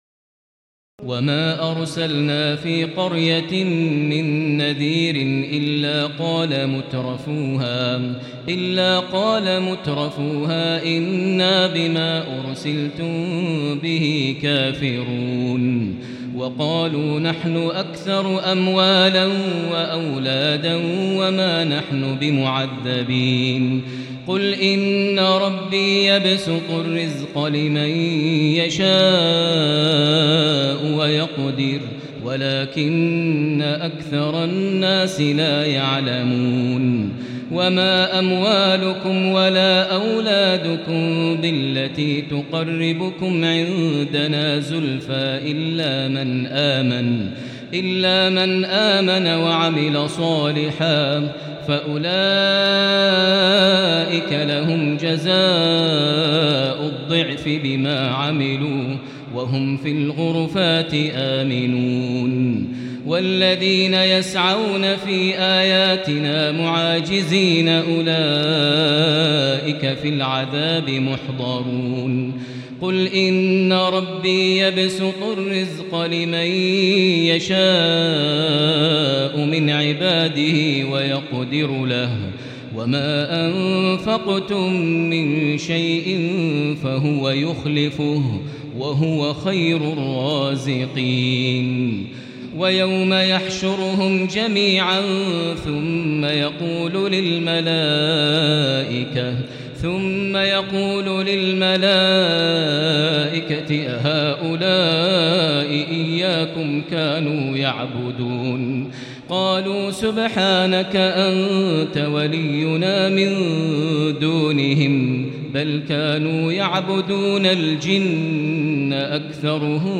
تراويح ليلة 21 رمضان 1438هـ من سور سبأ (34-54) وفاطر و يس(1-32) Taraweeh 21 st night Ramadan 1438H from Surah Saba and Faatir and Yaseen > تراويح الحرم المكي عام 1438 🕋 > التراويح - تلاوات الحرمين